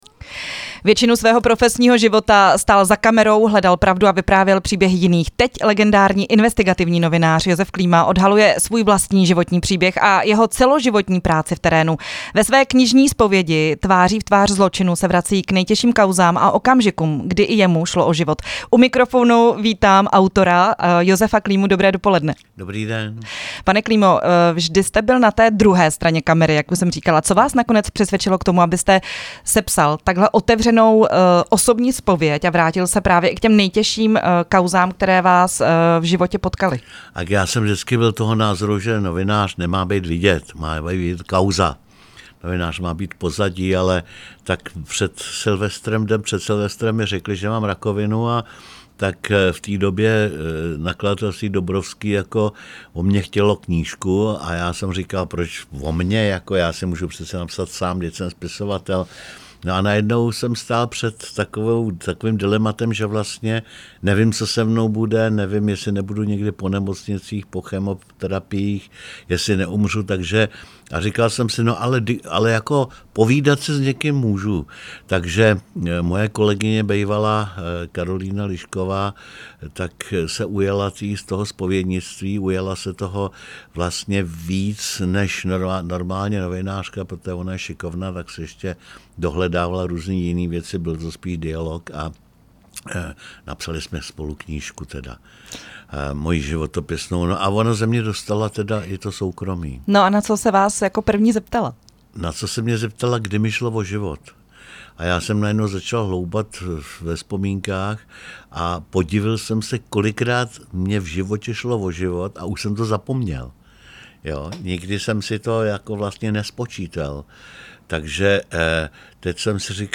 Rozhovor s novinářem Josefem Klímou